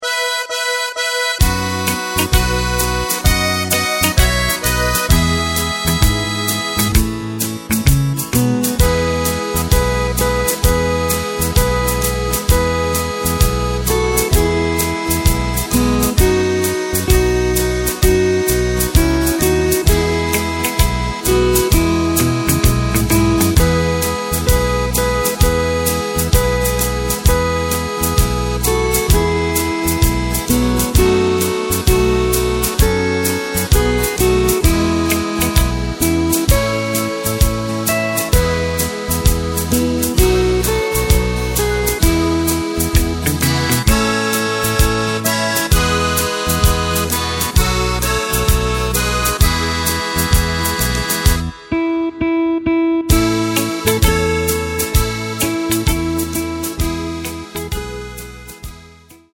Takt:          4/4
Tempo:         130.00
Tonart:            E
Schlager aus dem Jahr 1958!